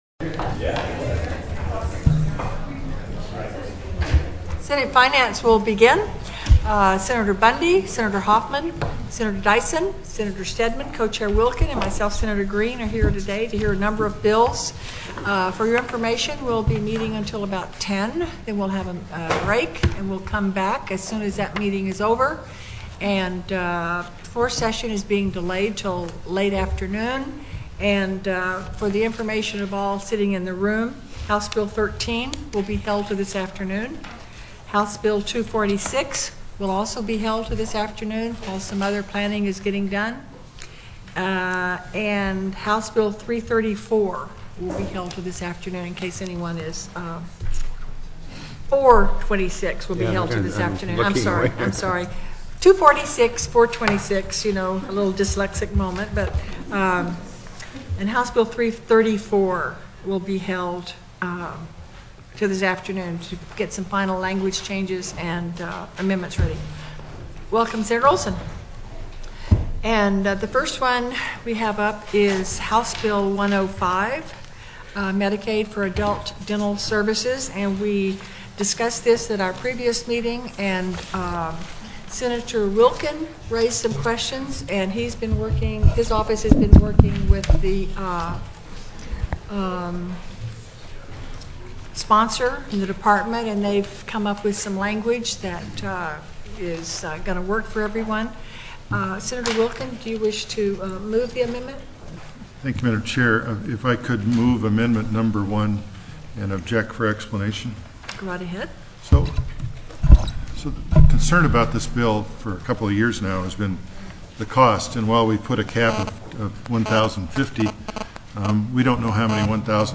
05/05/2006 09:00 AM Senate FINANCE
HB 105 MEDICAID FOR ADULT DENTAL SERVICES TELECONFERENCED